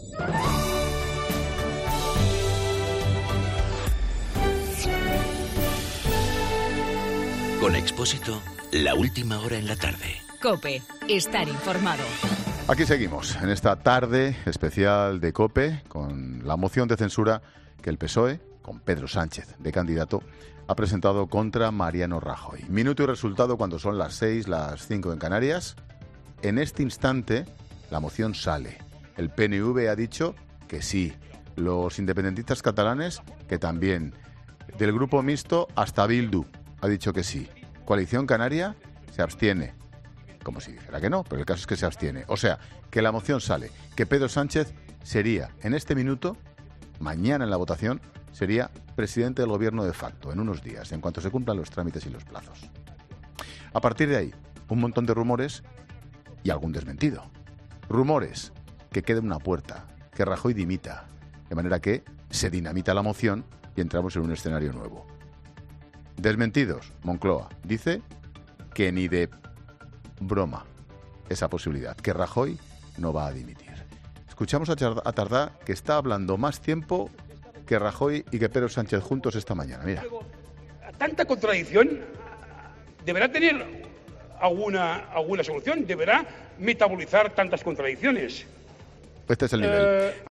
Monólogo de Expósito
El comentario de Ángel Expósito siguiendo la moción de censura en el Congreso.